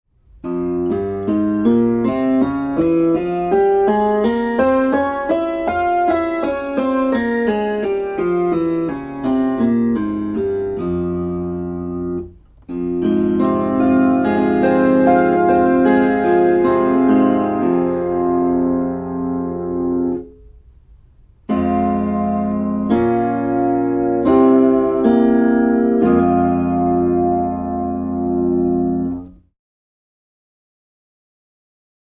Kuuntele f-molli. b as des Opettele duurit C G D A E F B Es As mollit a e h fis cis d g c Tästä pääset harjoittelun etusivulle.